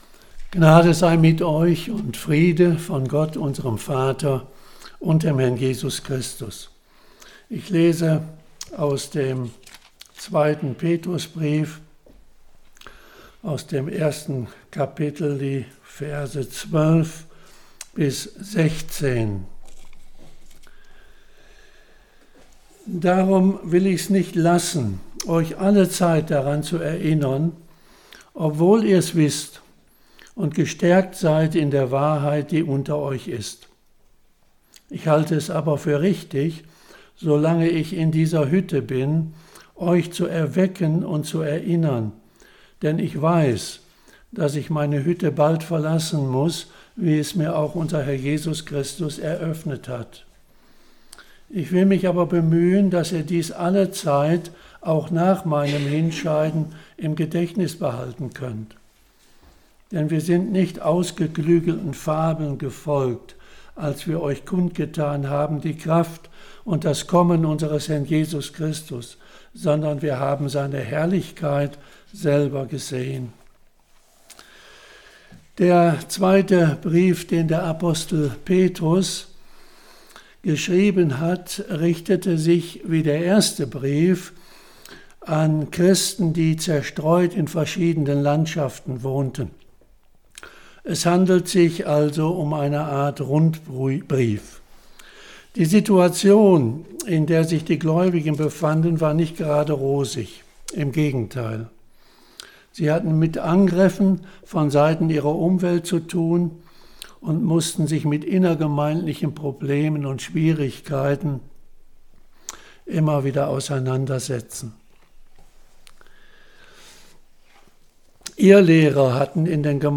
Predigten 2026